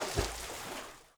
Water / Splashes
SPLASH_Subtle_03_mono.wav